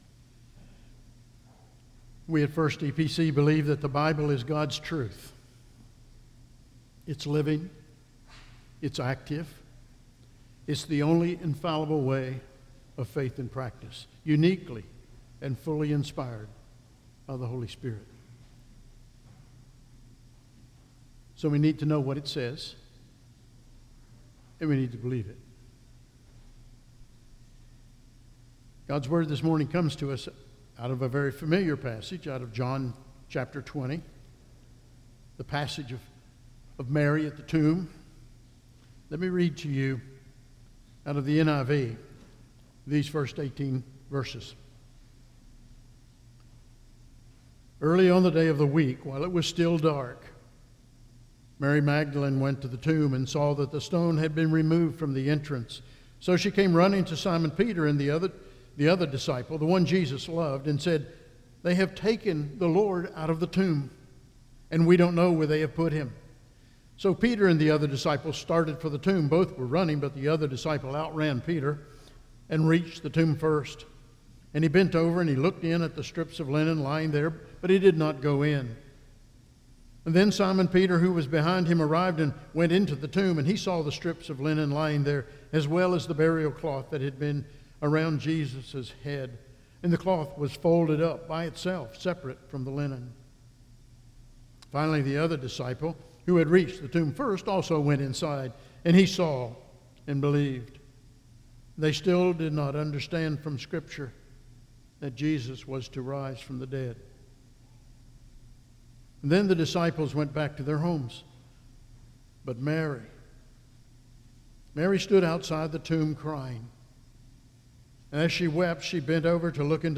Sermon audio from the pulpit of First Evangelical Presbyterian Church Roanoke